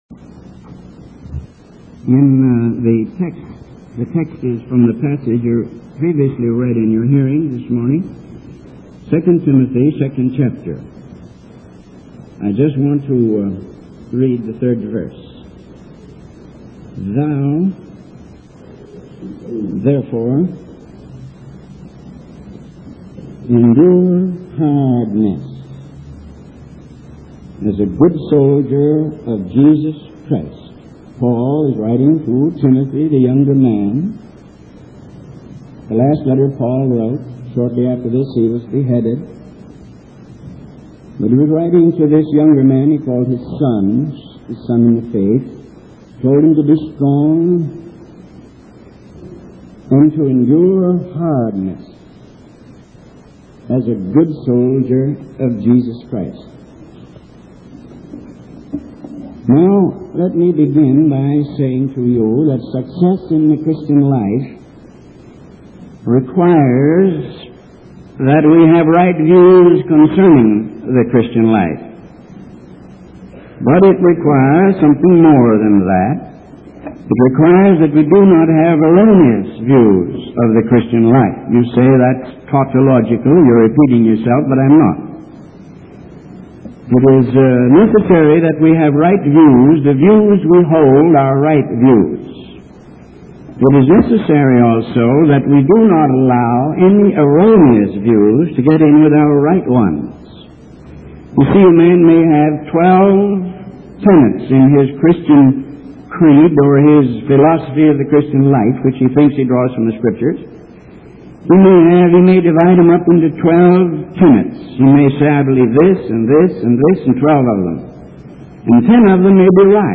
In this sermon, the speaker criticizes certain leaders who he believes are leading young people astray by portraying the Christian life as a game. He argues that this mindset affects various aspects of Christian culture, such as music and magazines.